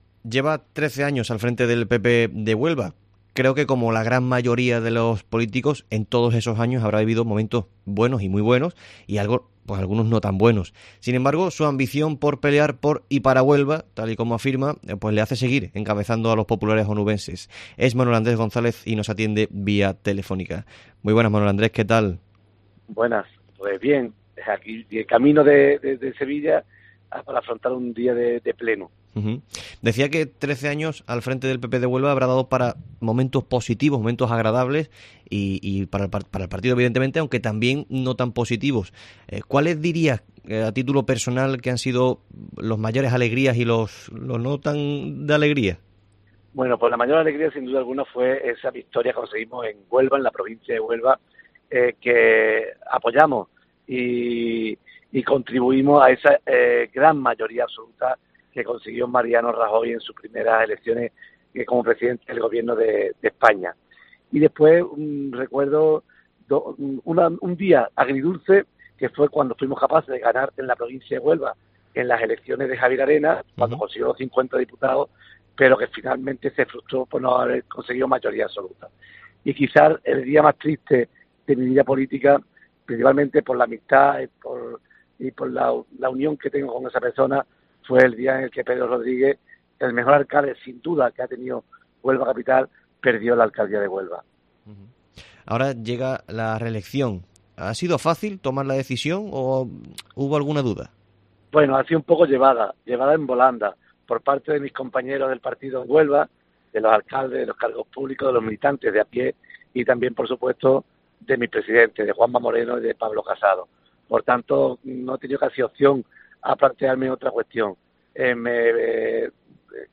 ENTREVISTA
El actual presidente del Partido Popular de Huelva ha atendido la llamada de COPE Huelva para contestar varias preguntas sobre la provincia y sobre la situación política actual.